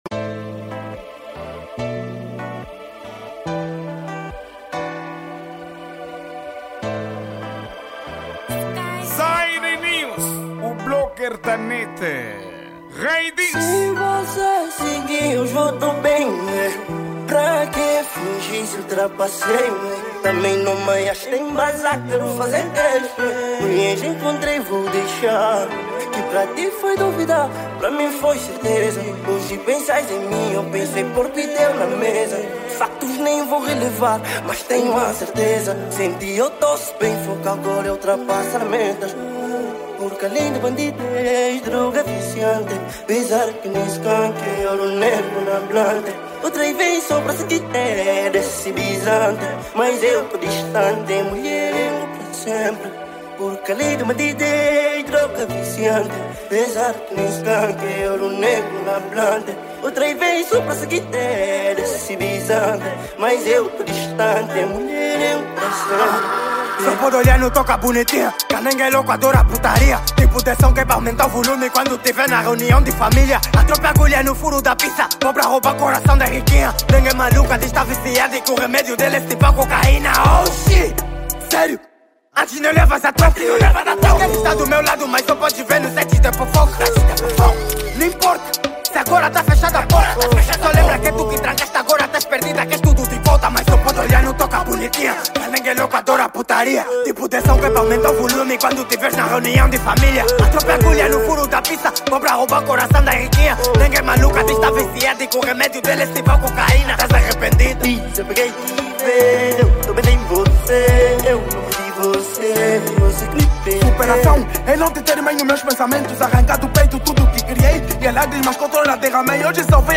Gênero: Rap